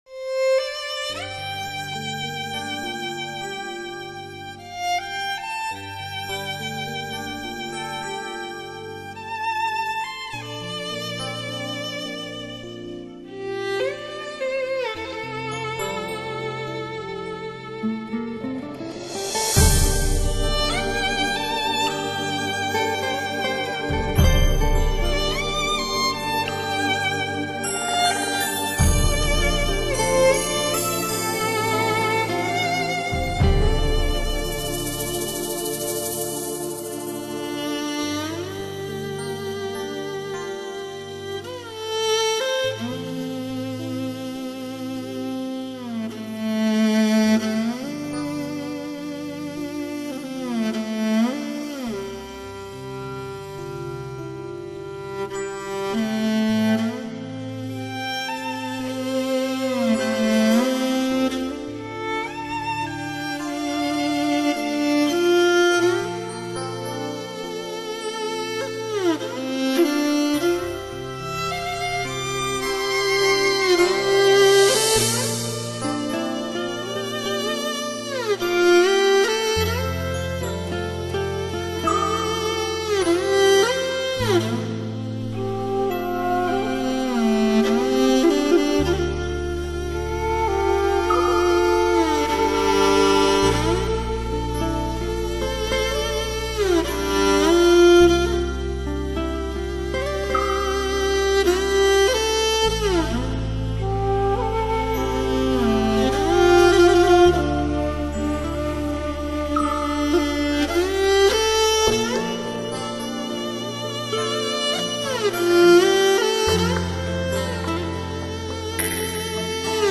迷幻电子乐精品演奏
让您在大草原中享受轻松舒缓的同时